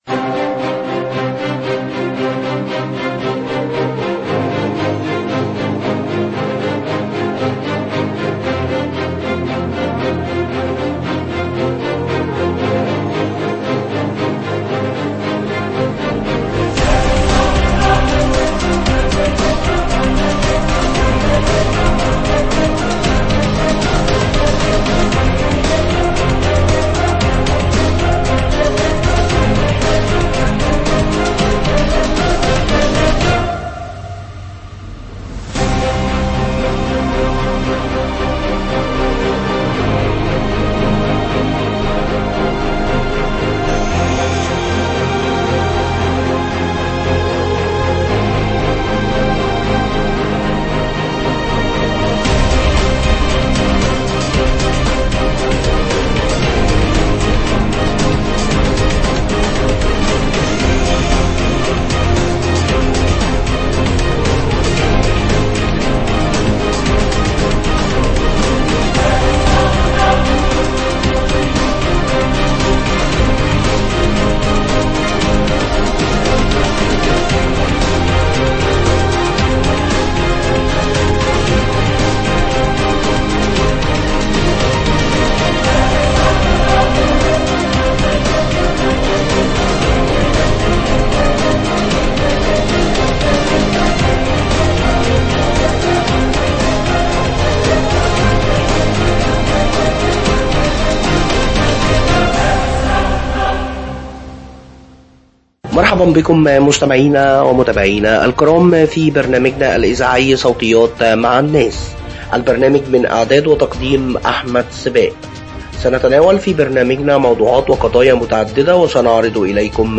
مرحبا بكم متابعينا الكرام فى برنامجنا الإذاعى صوتيات مع الناس